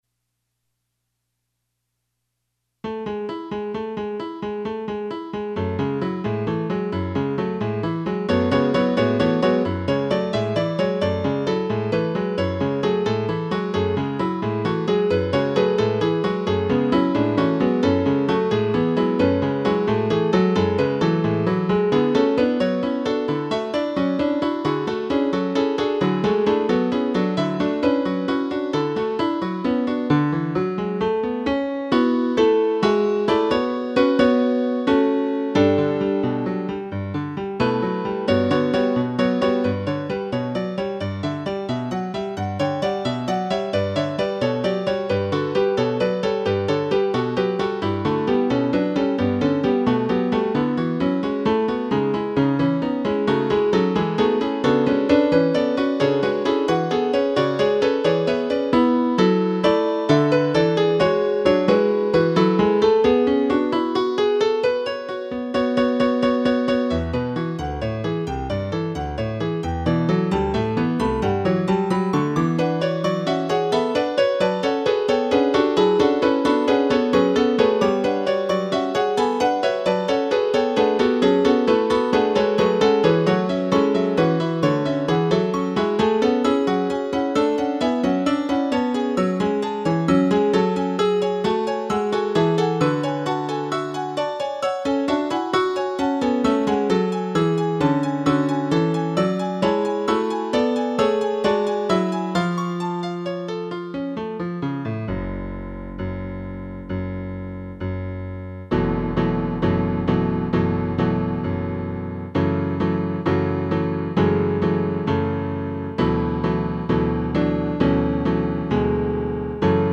Number of voices: 3vv Voicing: SAB Genre: Secular, Partsong
Language: English Instruments: Piano